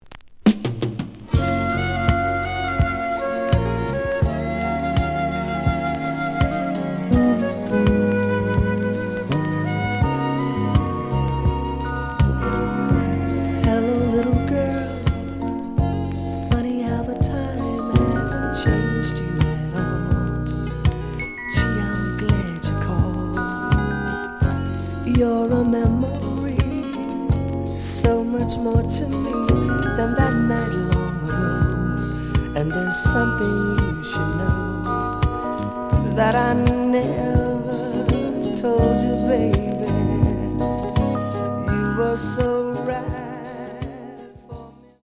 試聴は実際のレコードから録音しています。
The audio sample is recorded from the actual item.
●Format: 7 inch
●Genre: Soul, 70's Soul